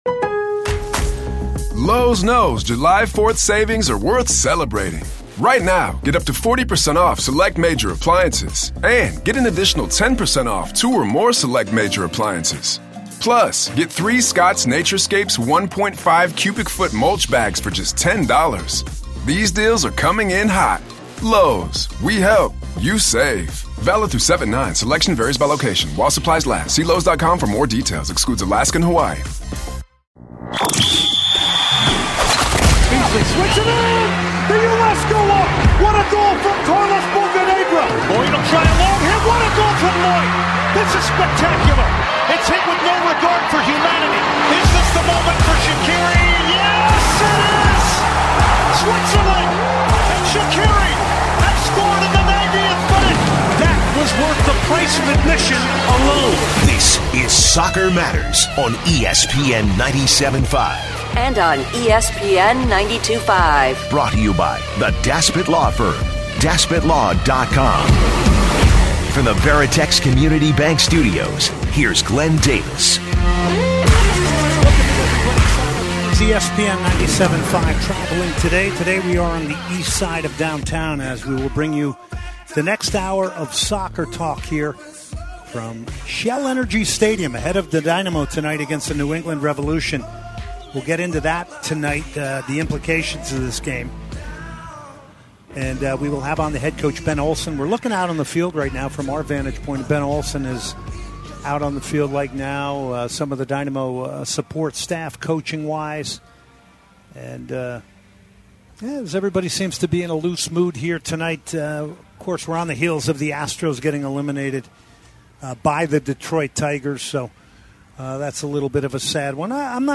10/02 - Interview with Dynamo Coach Ben Olsen, USMNT, Dynamo vs New England